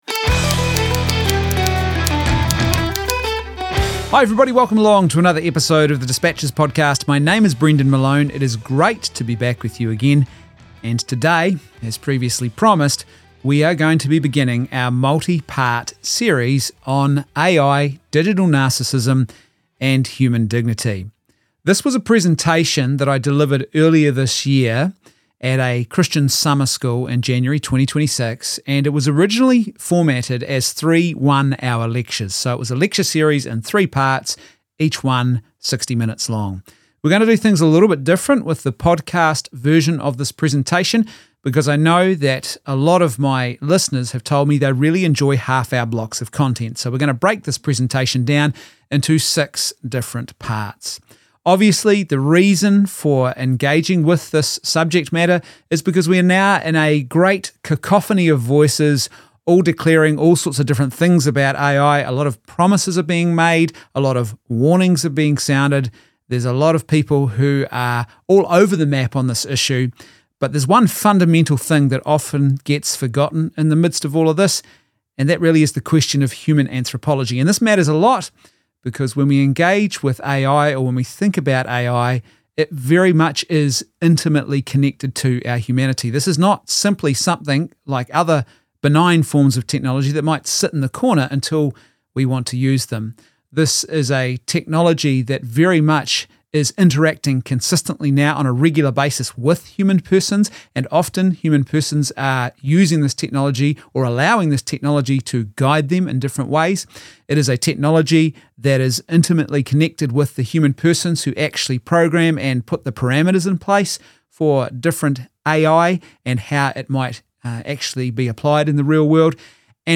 In this special 6 part podcast adaption of a lecture series that I was asked to present at an event in January 2026, we explore the issue of Artificial Intelligence in the light of Christian anthropology.